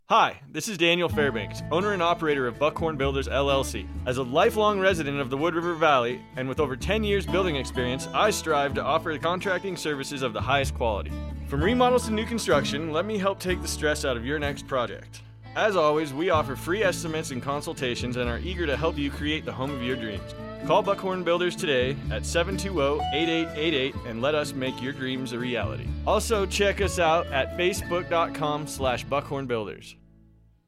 Buckhorn Builders Radio Ad